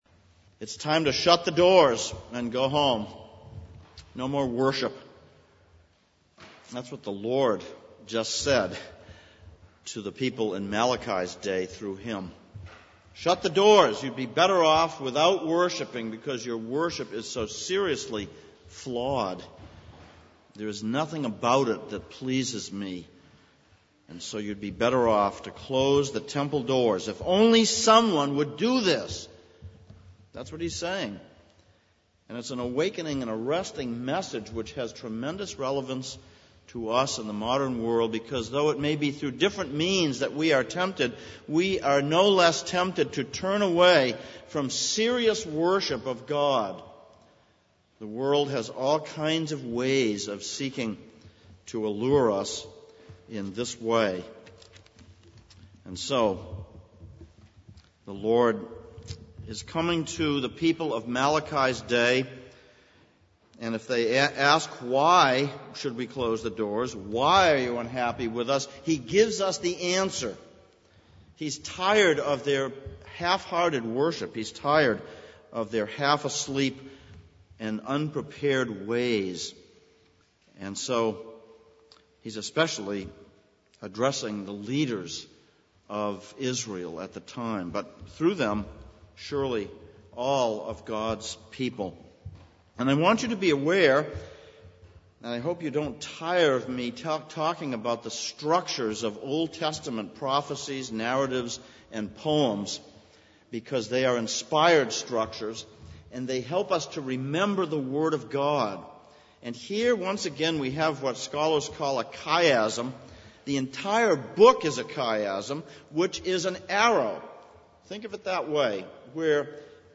Passage: Malachi 1:6-14, Colossians 3:1-7 Service Type: Sunday Morning